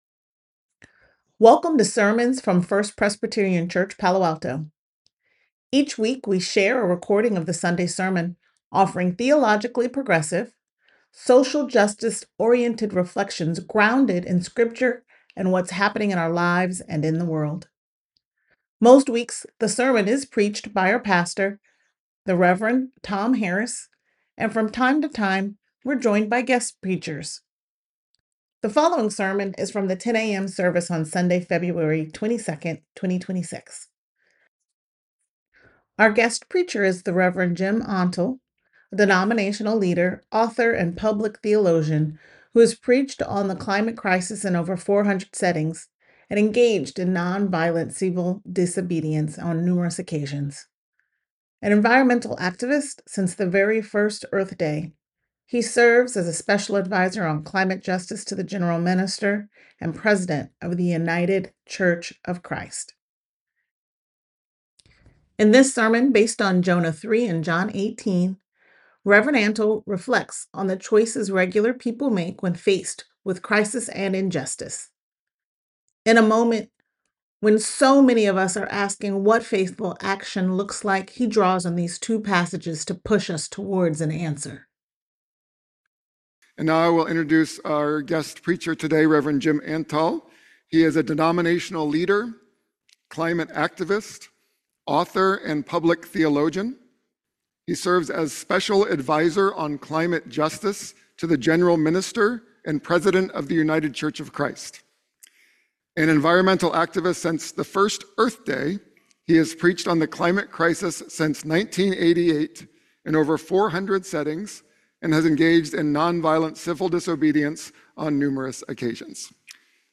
The following sermon is from the 10 a.m. service on Sunday, February 22nd, 2026.